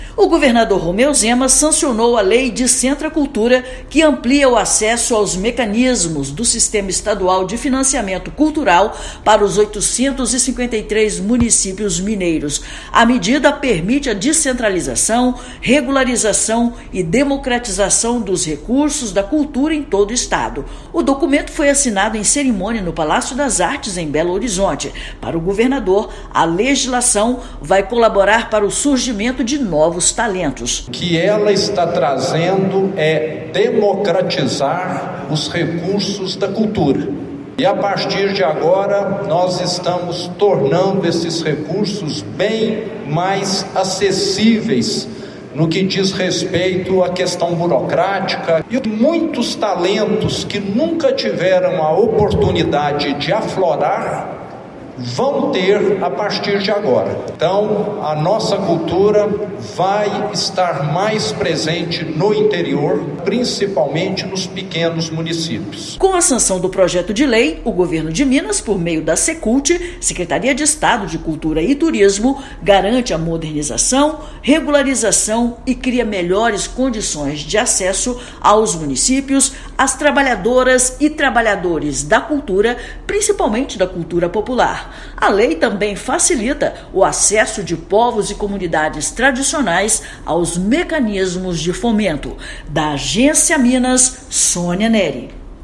Novas regras facilitam a chegada de recursos para os 853 municípios mineiros; legislação também melhora condições de participação para povos e comunidades tradicionais. Ouça matéria de rádio.